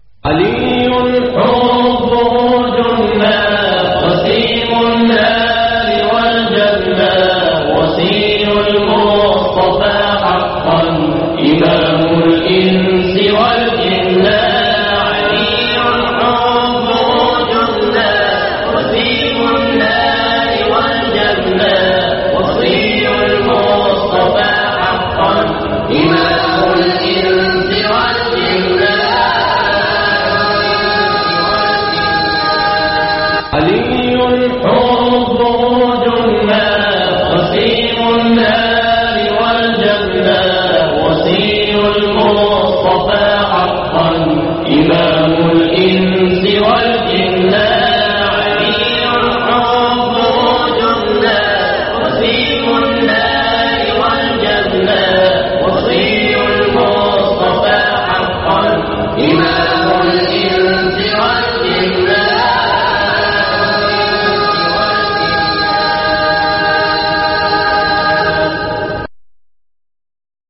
علي حبه جنه ـ مقام البيات - لحفظ الملف في مجلد خاص اضغط بالزر الأيمن هنا ثم اختر (حفظ الهدف باسم - Save Target As) واختر المكان المناسب